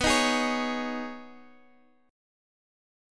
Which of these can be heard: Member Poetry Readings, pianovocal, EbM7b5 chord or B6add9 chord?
B6add9 chord